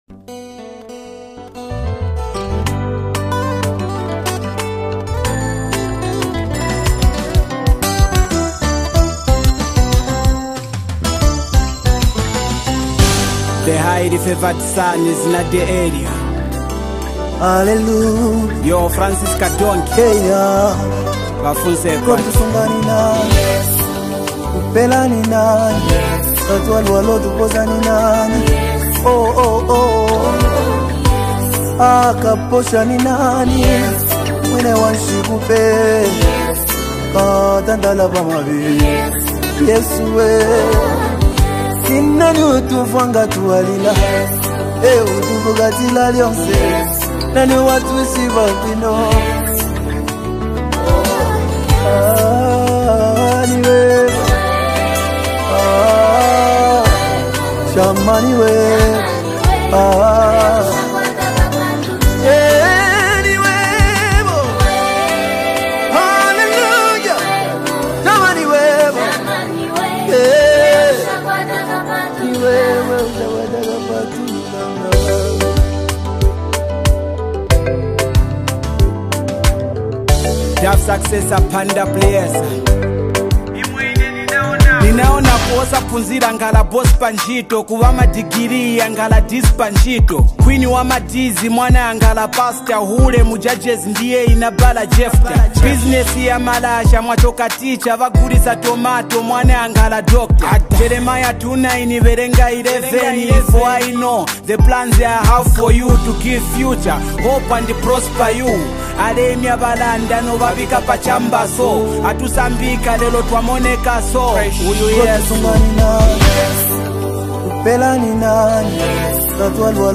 LATEST ZAMBIAN RUMBA GOSPEL SONG 2025